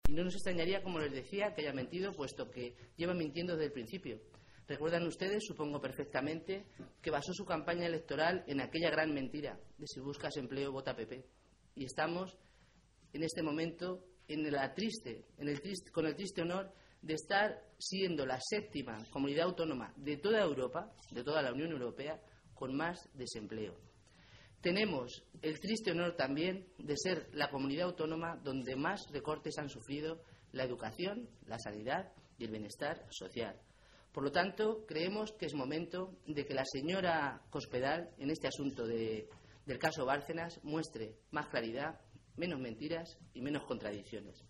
Rosa Melchor, diputada regional del Grupo Socialista
Cortes de audio de la rueda de prensa